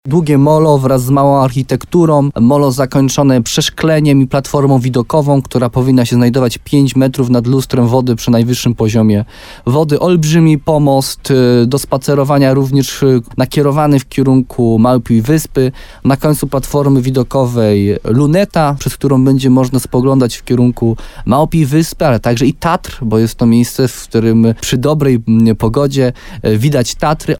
– To otwiera możliwość składania wniosków o dofinansowanie rewitalizacji ze środków rządowych albo unijnych – mówi wójt Jarosław Baziak.